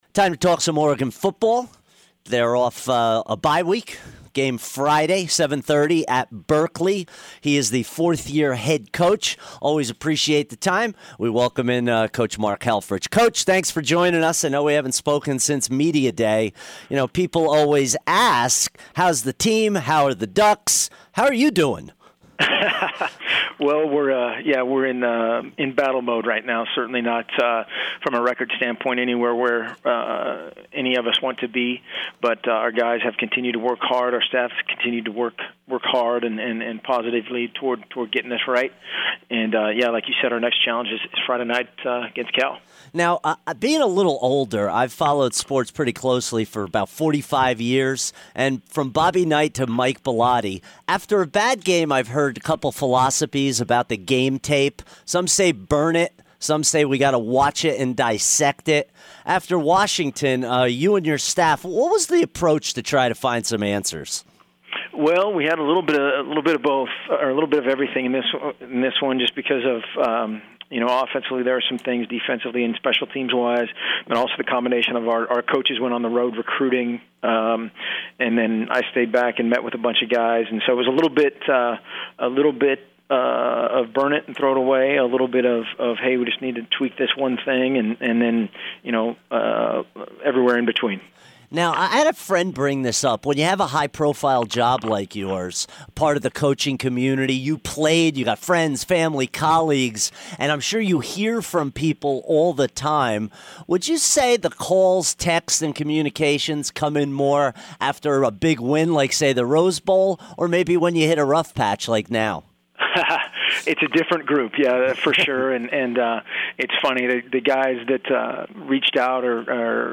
Coach Mark Helfrich Interview 10-19-16